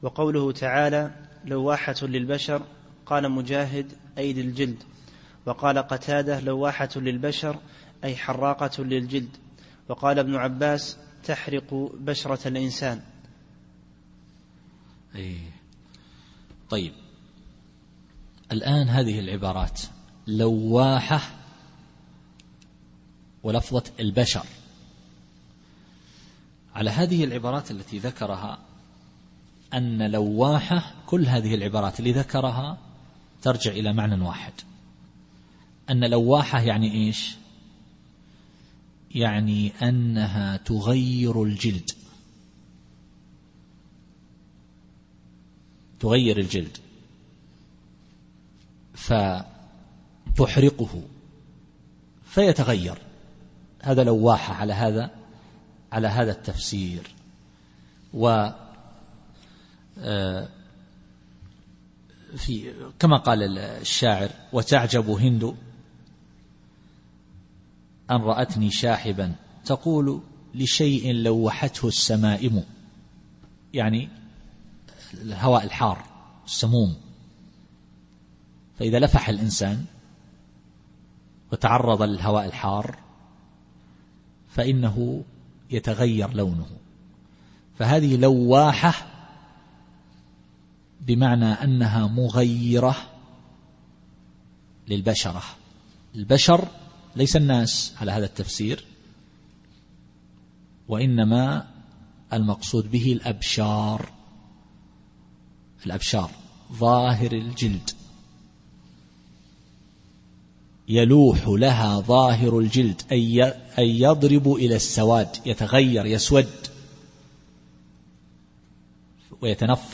التفسير الصوتي [المدثر / 29]